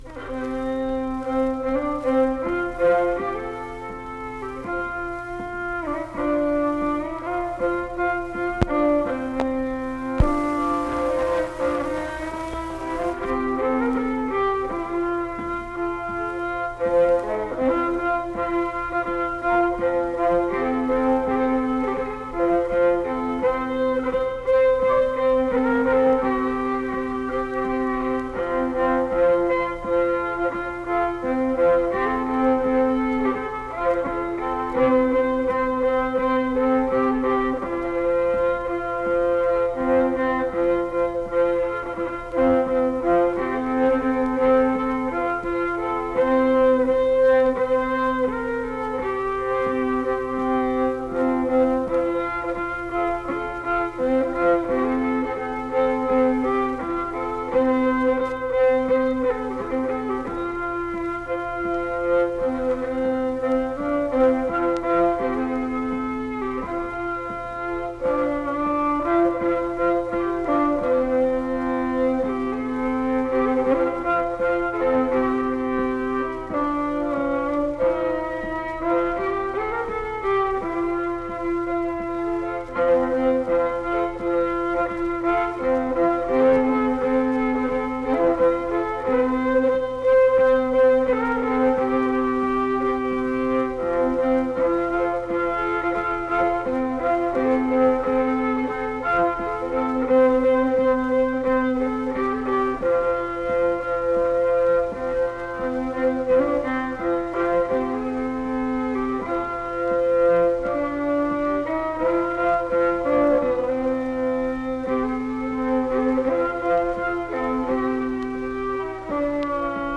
Unaccompanied fiddle music
Hymns and Spiritual Music, Instrumental Music
Fiddle
Pocahontas County (W. Va.)